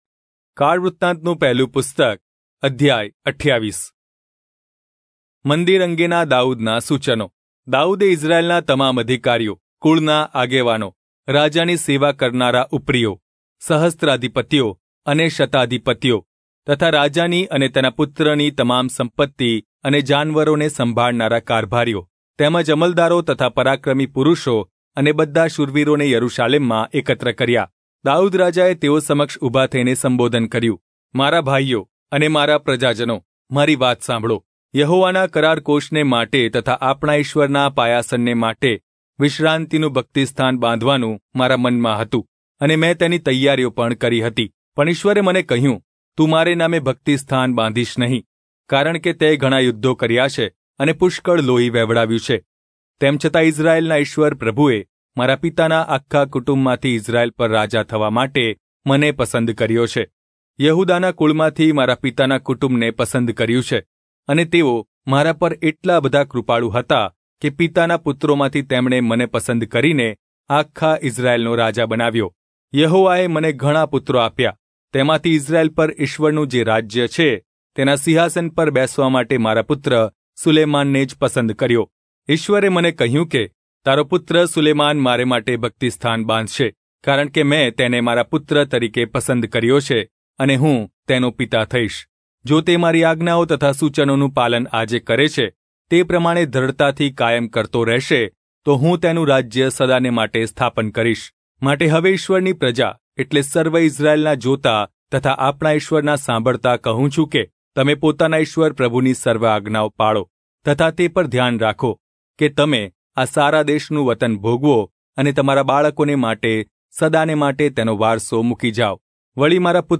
Gujarati Audio Bible - 1-Chronicles 16 in Irvgu bible version